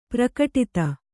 ♪ prakaṭita